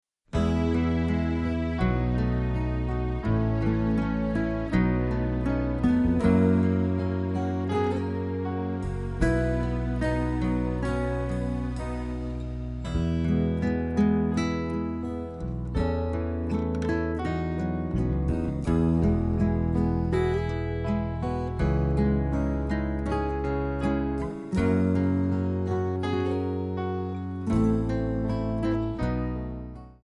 Backing track files: 1970s (954)
Buy With Backing Vocals.